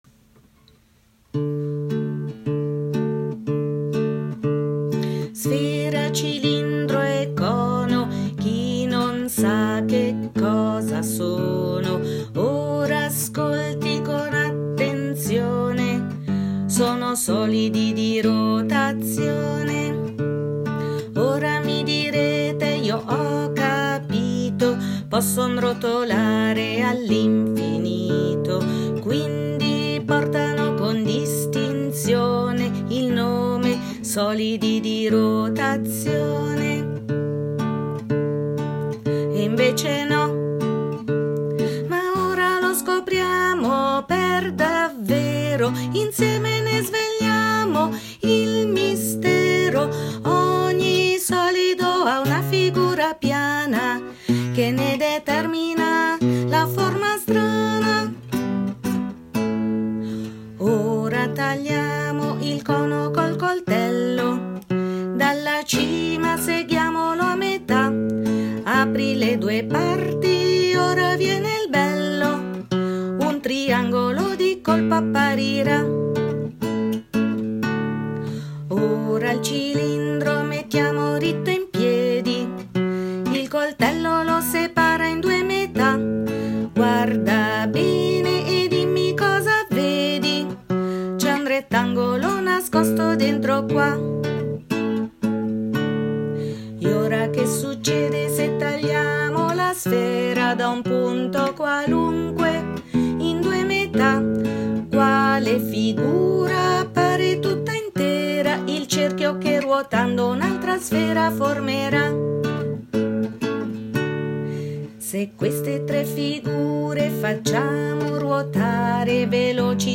canzone
07-I-SOLIDI-DI-ROTAZIONE-CANTO.m4a